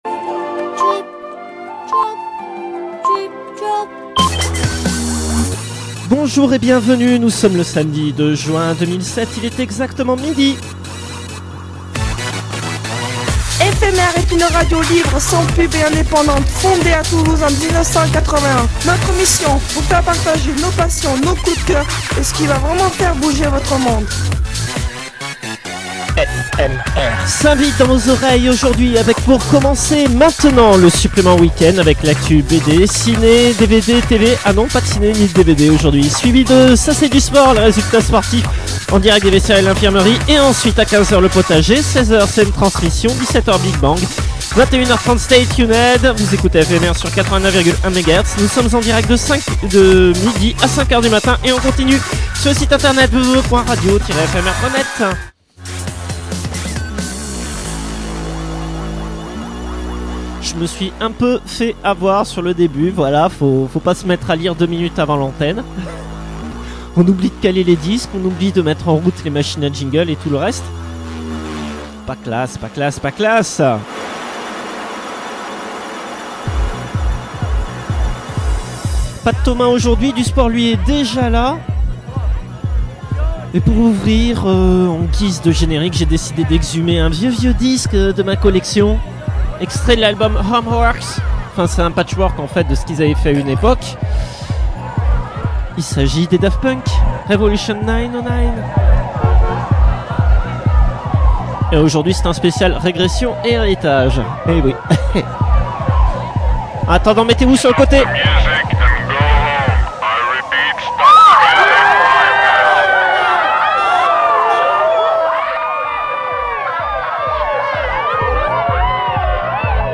INTERVIEW
Les Exilés » (enregistré à Angoulême en Janvier 2007)